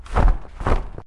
Heroes3_-_Crimson_Couatl_-_MoveSound.ogg